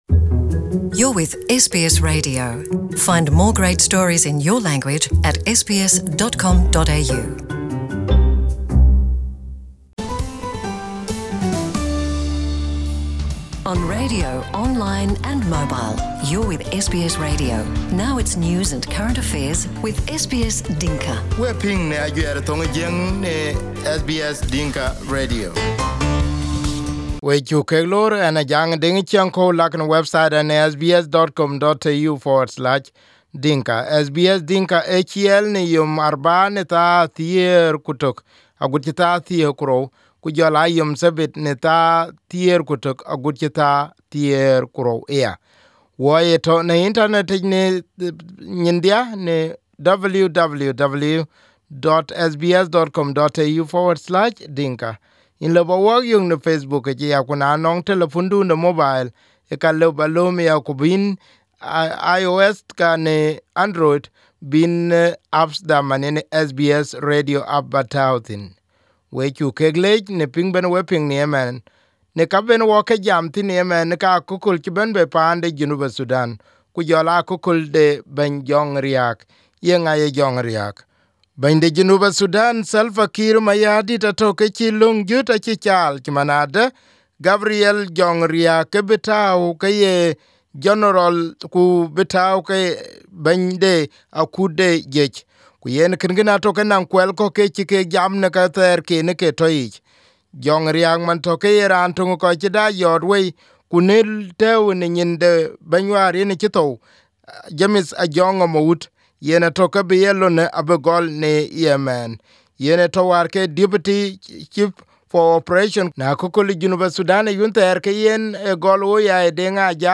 Here is the part of interview that SBS Dinka conducted while he was in hospital in Kenya.